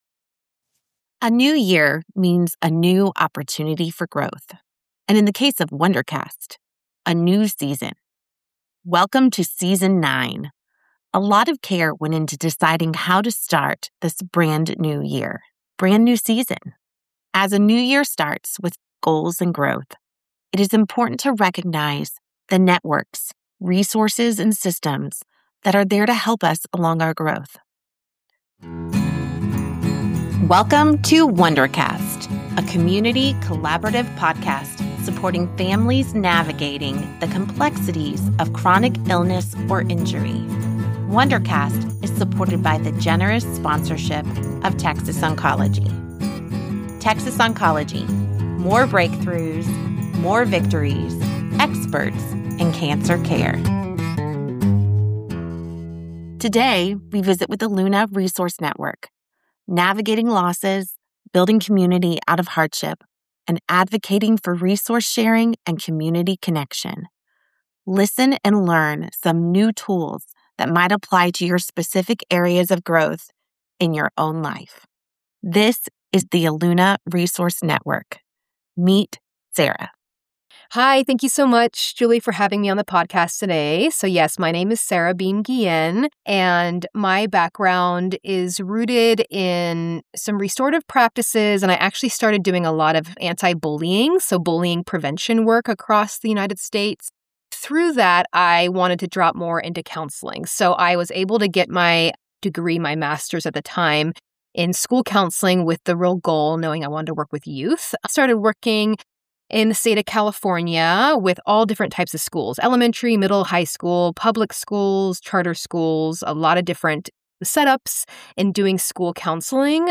The conversation highlights the need for open discussions about grief and addiction, aiming to reduce stigma and encourage families to seek help.